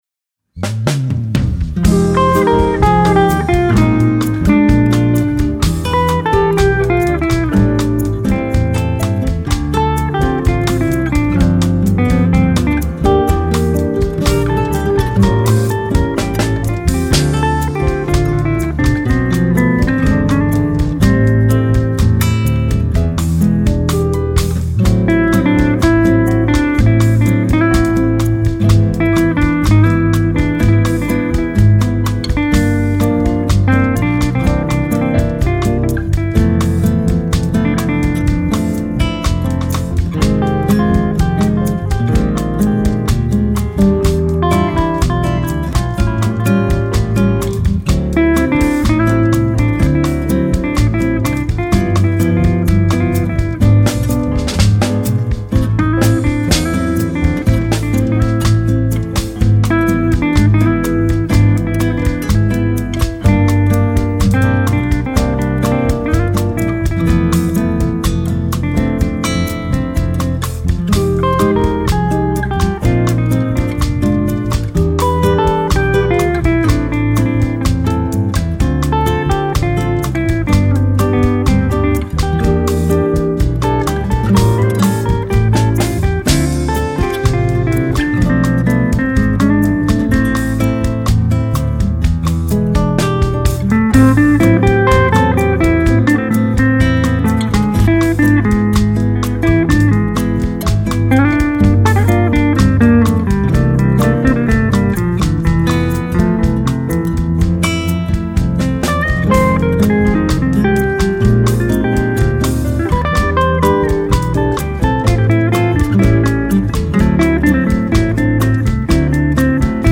758   05:29:00   Faixa:     Mpb
Guitarra, Bandolim
Flauta, Saxofone Tenor
Piano Acústico